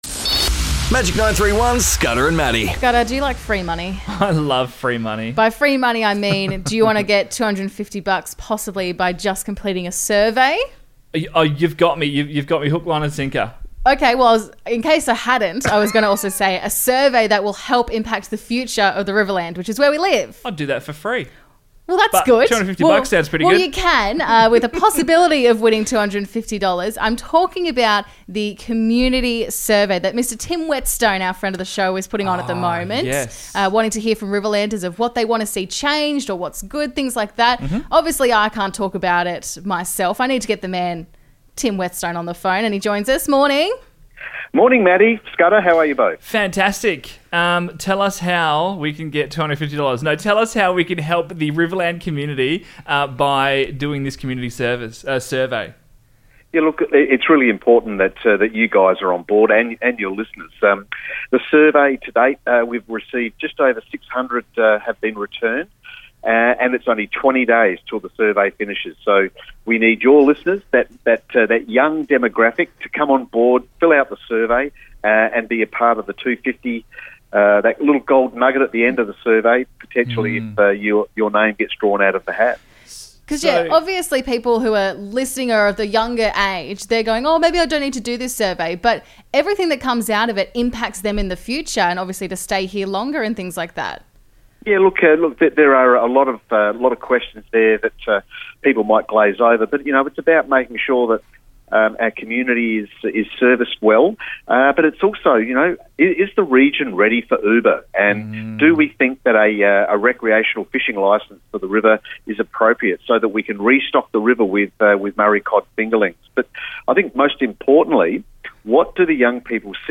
came on air with us this morning to chat about the Community Survey happening now, and the chance to win $250!